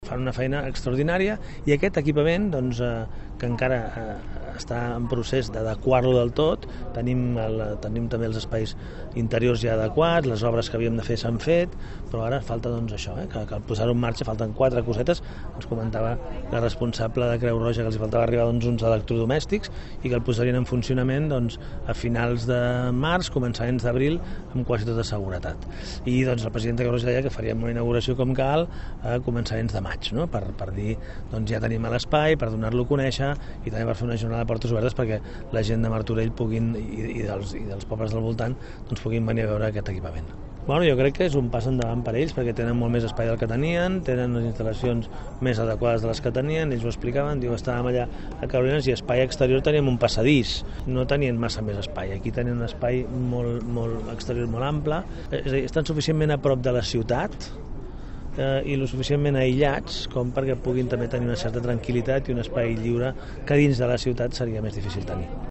Visita institucional aquest matí a la nova ubicació de l’equipament de Creu Roja Baix Llobregat Nord, situat a la finca de la Torrassa.
Xavier Fonollosa, alcalde de Martorell